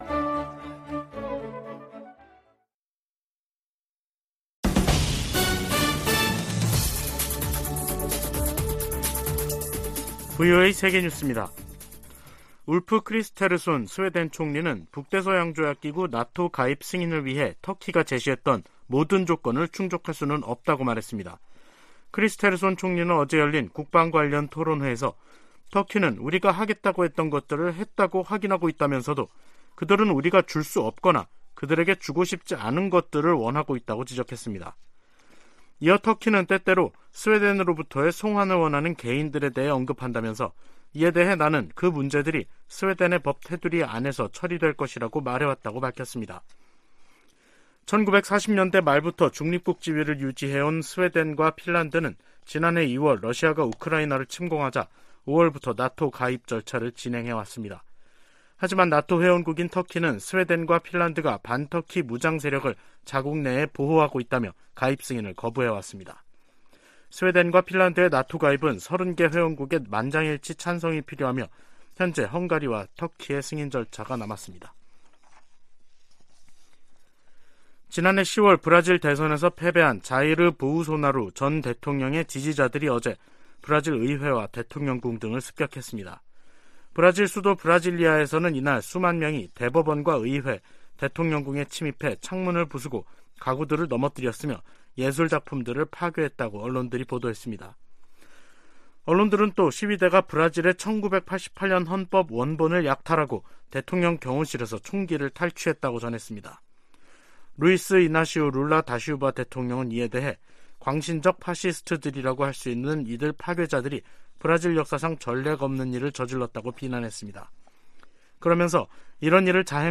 VOA 한국어 간판 뉴스 프로그램 '뉴스 투데이', 2023년 1월 9일 2부 방송입니다. 미 국무부는 6년째 공석인 북한인권특사 임명 여부와 관계 없이 미국은 북한 인권 문제에 집중하고 있다고 밝혔습니다. 미국의 한반도 전문가들은 실효성 논란에 휩싸인 9.19 남북 군사합의와 관련해, 이를 폐기하기보다 북한의 도발에 대응한 ‘비례적 운용’이 더 효과적이라고 제안했습니다.